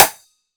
Glassy Rim.wav